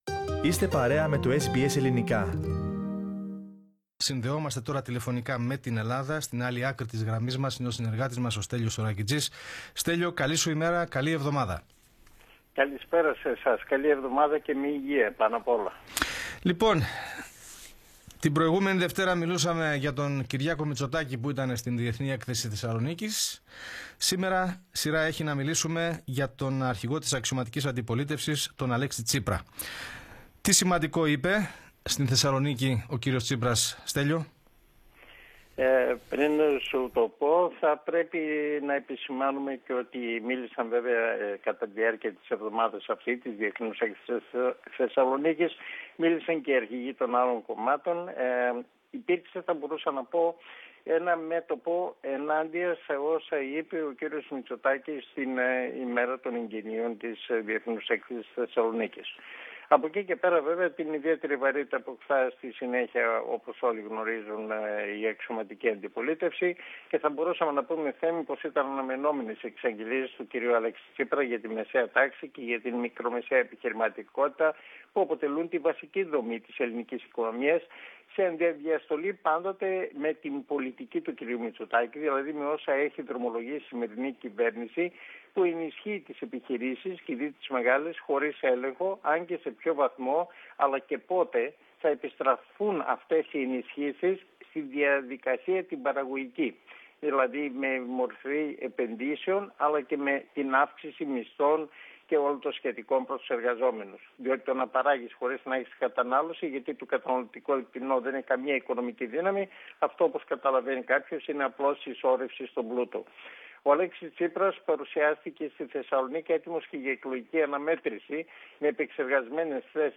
Πατήστε PLAY πάνω στην εικόνα για να ακούσετε την ανταπόκριση του SBS Greek/SBS Ελληνικά από την Ελλάδα.